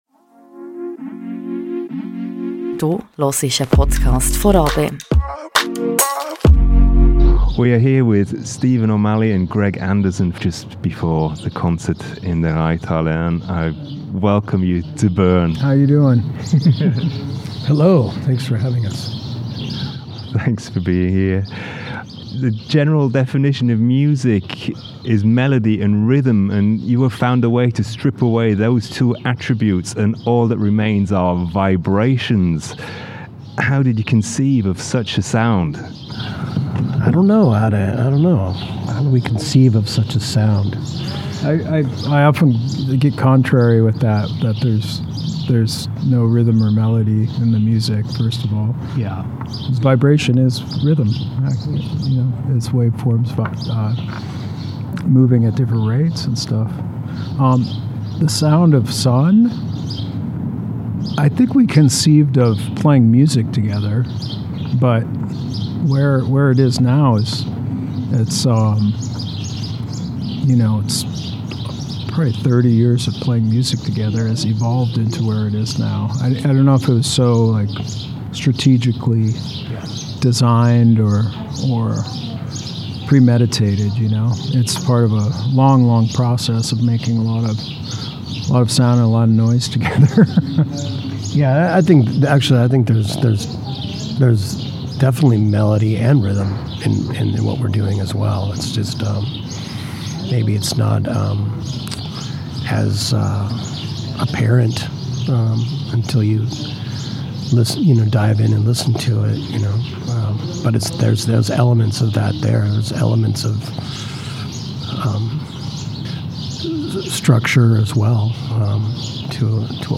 Beschreibung vor 9 Monaten It was a beautiful summer evening as I sat down in the courtyard of the Reithalle in Bern with Stephen O'Malley and Greg Andersen of SUNN O))). We talked about their music, how important their amps are for their sound, how it feels to create such monumental soundscapes and more… and as we were outdoors, the local birds chimed in on the conversation.